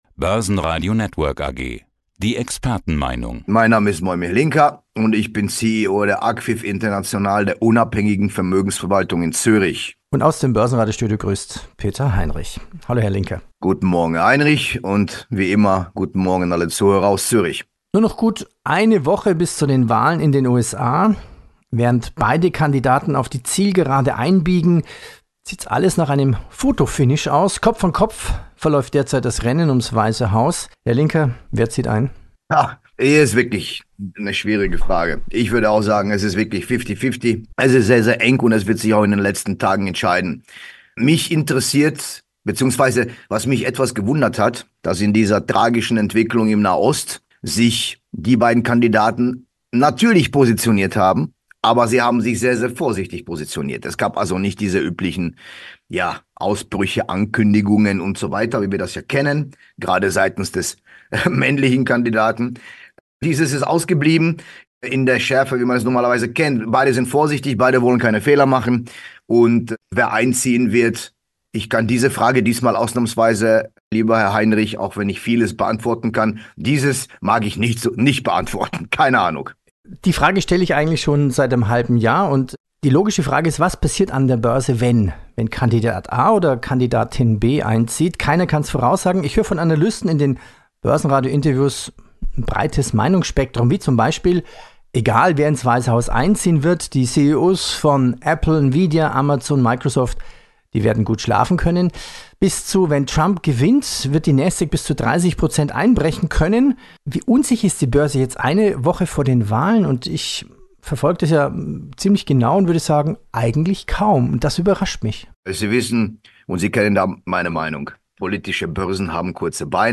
Eidg. dipl. Finanz- und Anlageexperte) im Gespräch